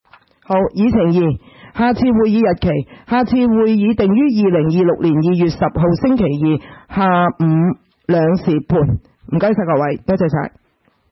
會議的錄音記錄
交通運輸委員會特別會議會議 日期: 2025-12-17 (星期三) 時間: 下午2時30分 地點: 香港中環統一碼頭道38號海港政府大樓14字樓 離島區議會會議室 議程 討論時間 I. 優化港口布局: 建議重新規劃南丫島以南的港口設施和設立喜靈洲危險品碇泊處 01:07:53 II. 下次會議日期 00:00:11 全部展開 全部收回 議程:I. 優化港口布局: 建議重新規劃南丫島以南的港口設施和設立喜靈洲危險品碇泊處 討論時間: 01:07:53 前一頁 返回頁首 議程:II. 下次會議日期 討論時間: 00:00:11 前一頁 返回頁首 如欲參閱以上文件所載檔案較大的附件或受版權保護的附件，請向 區議會秘書處 或有關版權持有人（按情況）查詢。